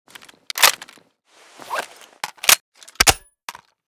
ump45_reload_empty.ogg